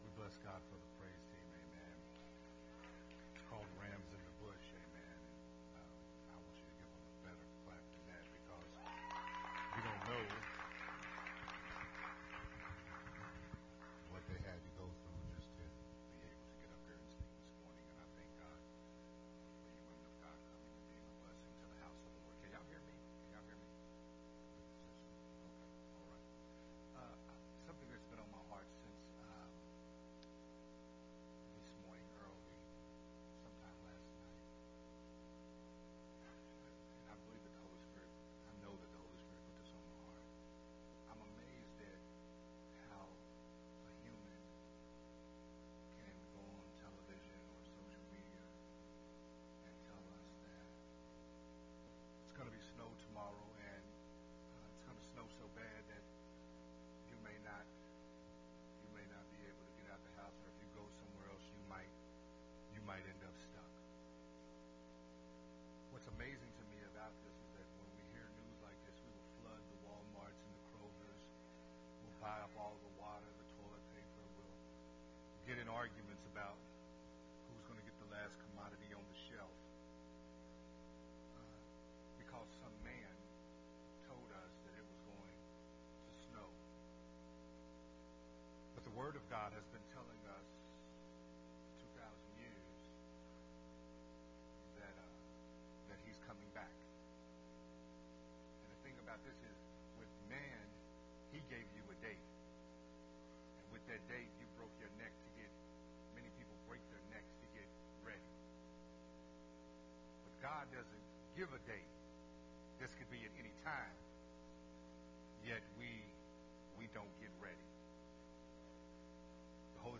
sermon series
recorded at Unity Worship Center